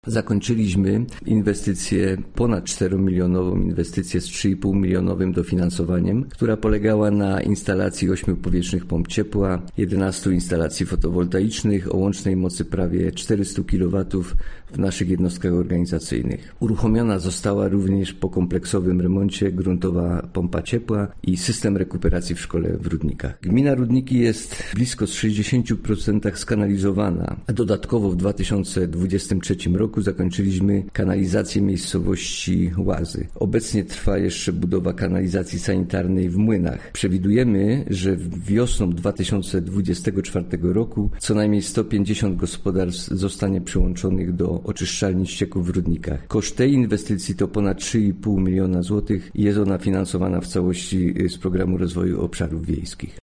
– mówił wójt Rudnik, Grzegorz Domański.